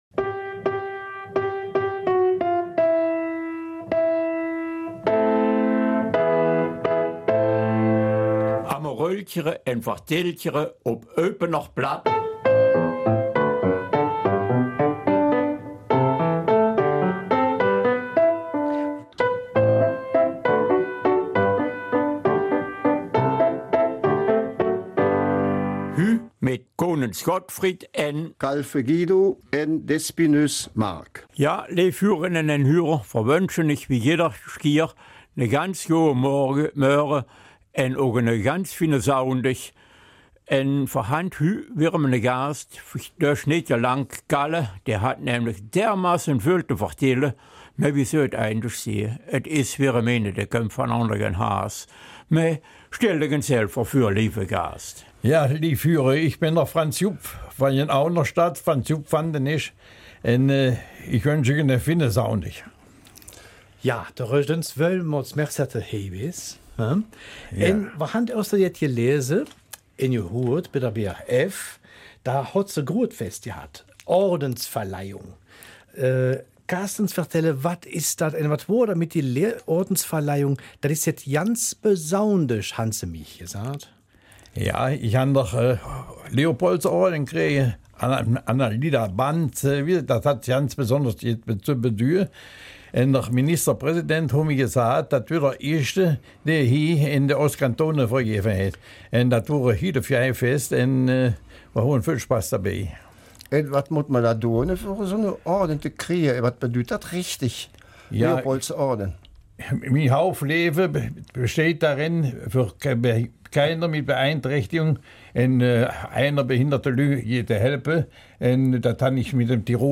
Eupener Mundart: Aufgeben ist nicht!